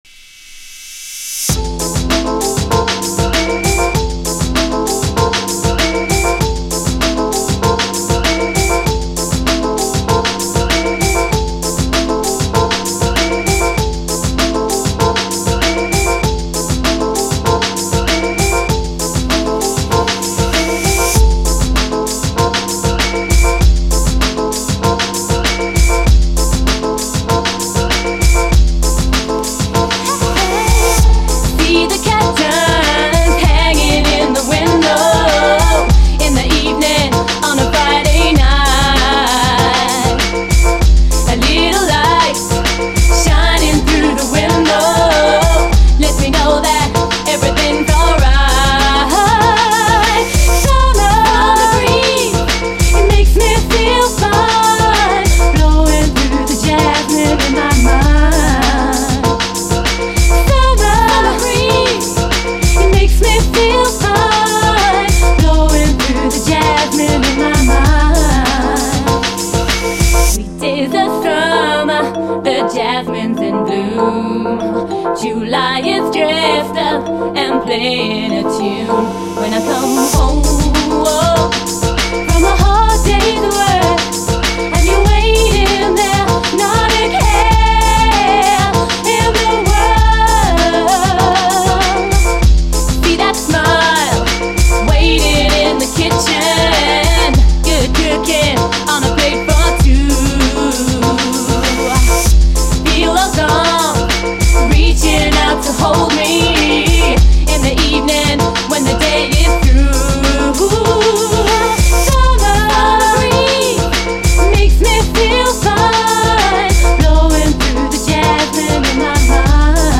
SOUL, 70's～ SOUL, DISCO
90年マイナーUKストリート・ソウル12インチ！